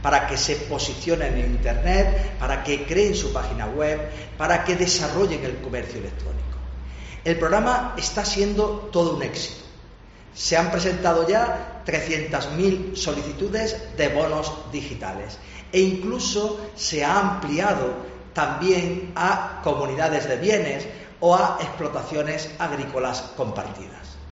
Jose Antonio Montilla, subdelegado del gobierno